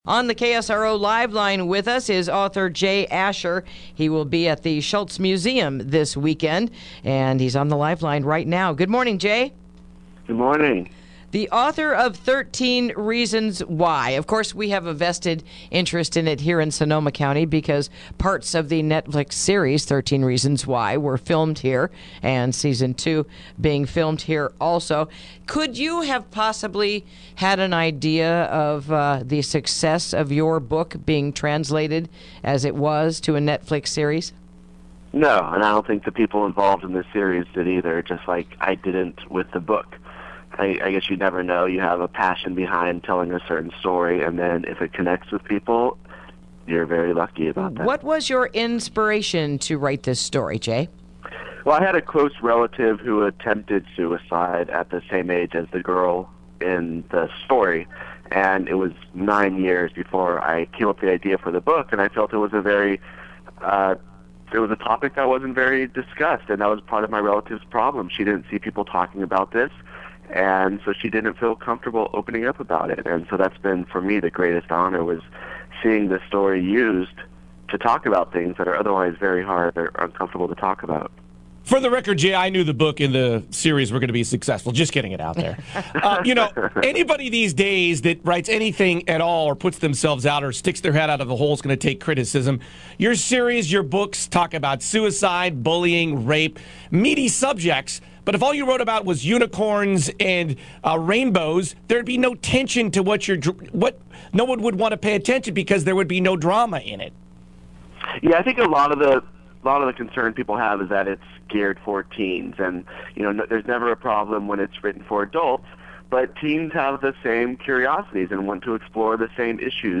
Interview: Author Jay Asher Apperance at the Schulz Museum